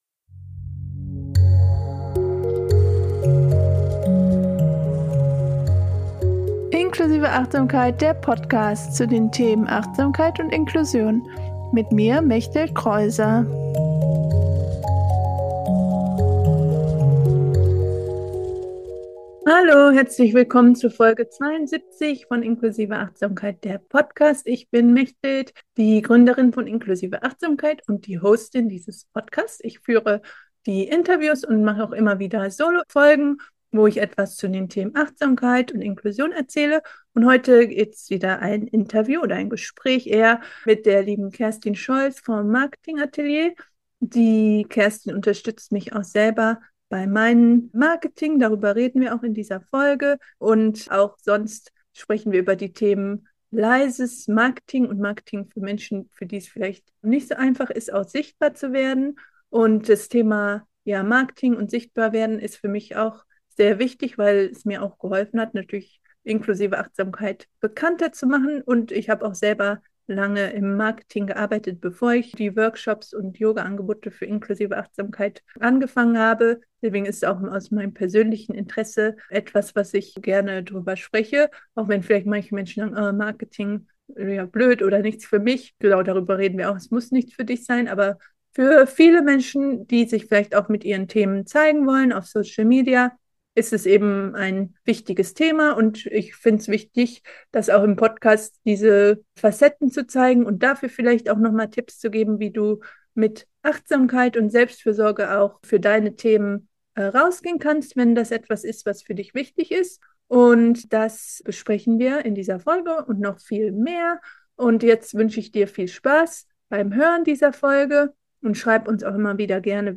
72 - Achtsames Marketing für Introvertierte und Hochsensible - Interview